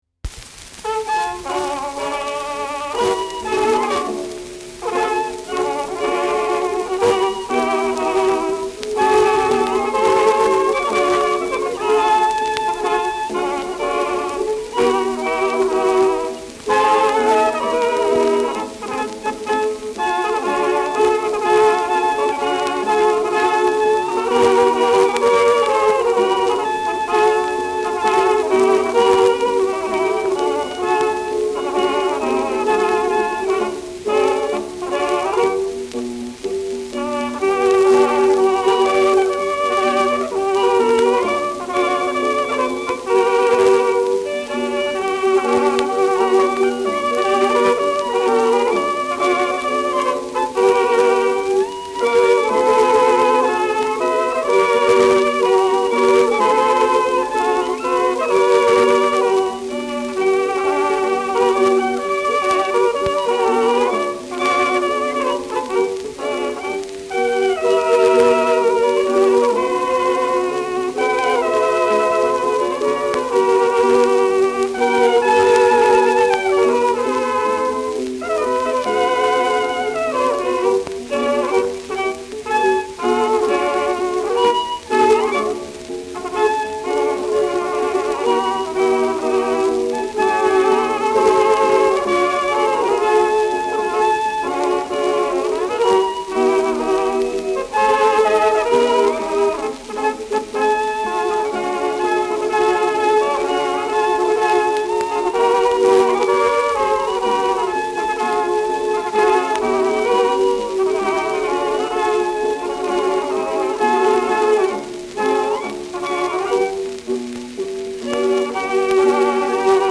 Schellackplatte
[Wien] (Aufnahmeort)